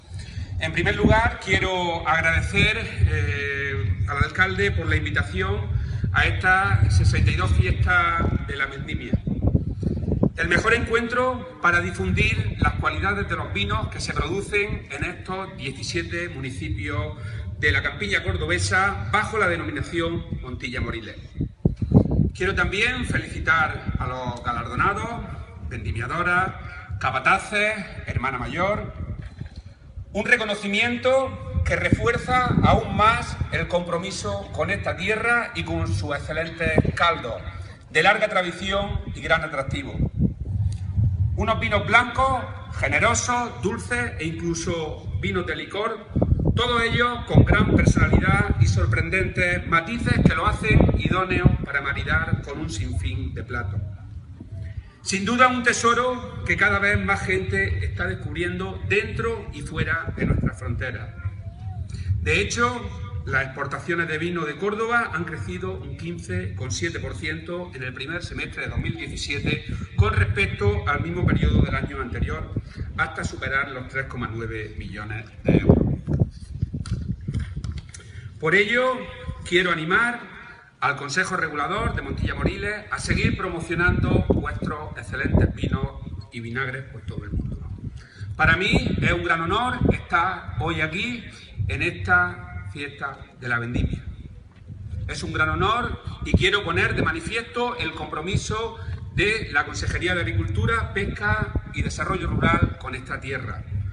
Declaraciones de Rodrigo Sánchez Haro en la Fiesta de la Vendimia de Montilla (Córdoba